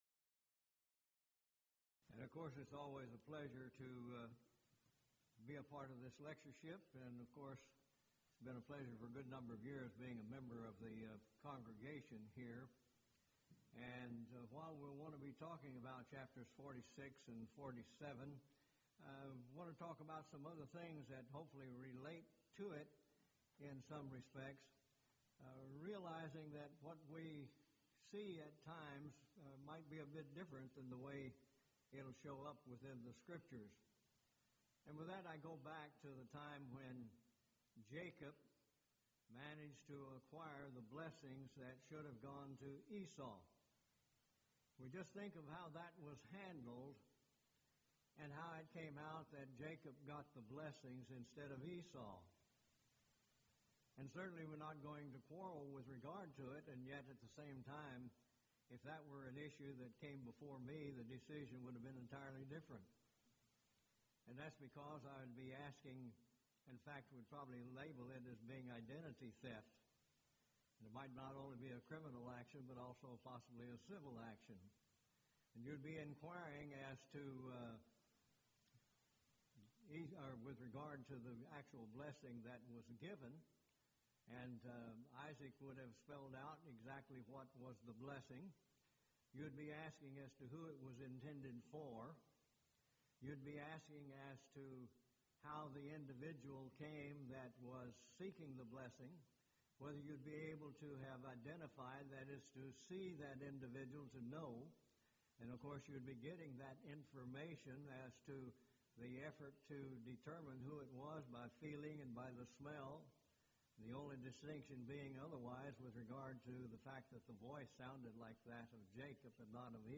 Event: 16th Annual Schertz Lectures Theme/Title: Studies in Genesis
lecture